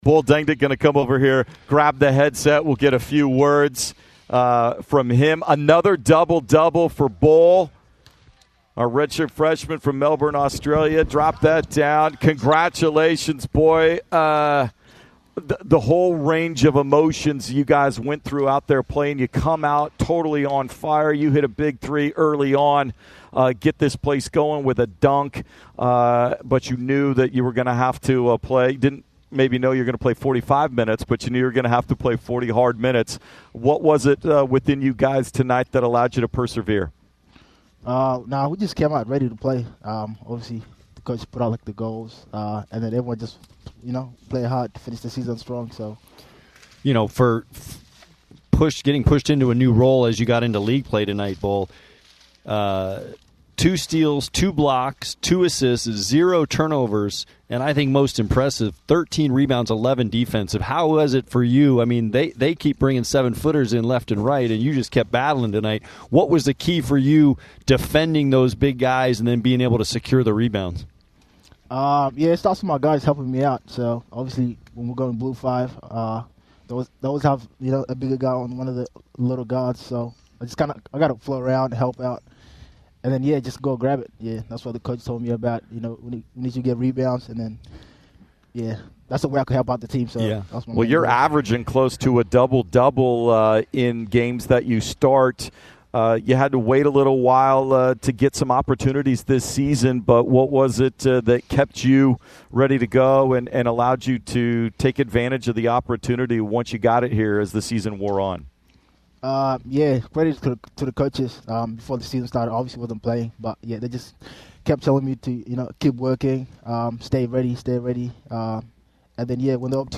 Post-Game Interview vs. Santa Clara